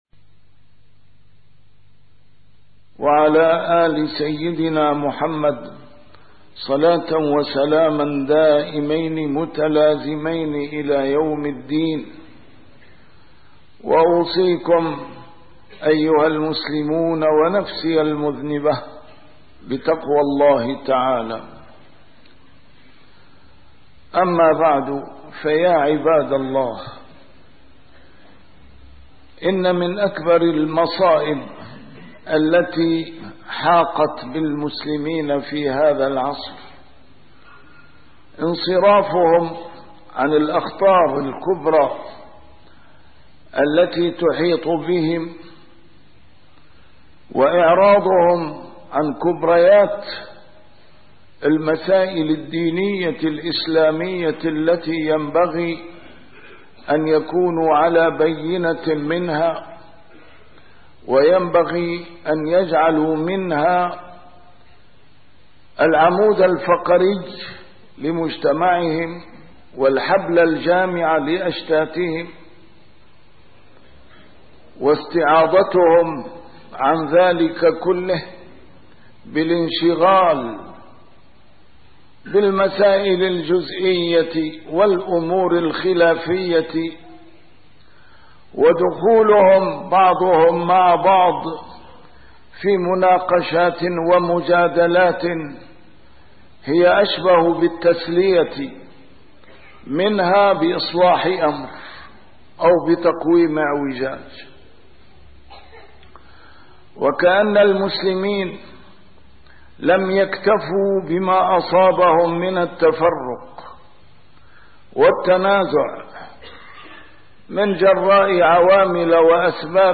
A MARTYR SCHOLAR: IMAM MUHAMMAD SAEED RAMADAN AL-BOUTI - الخطب - ارحموا الدين .. نداء إلى مثيري الجدل والشقاق